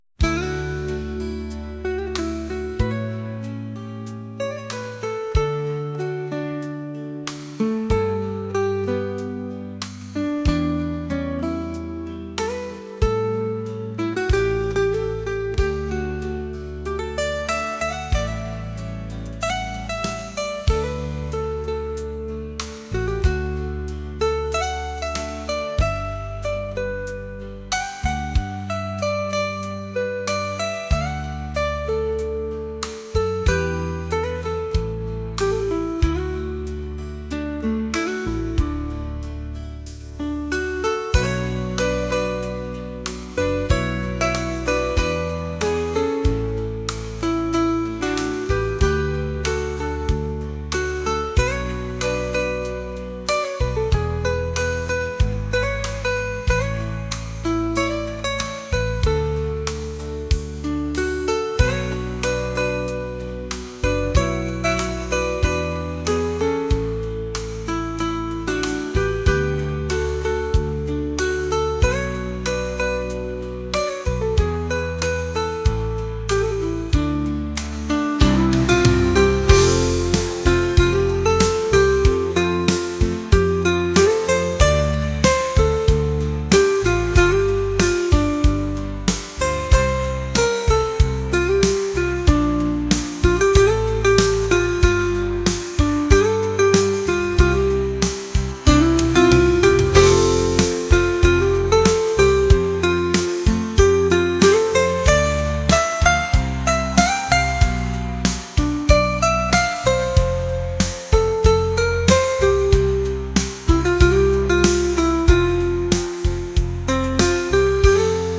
rock | acoustic | soul & rnb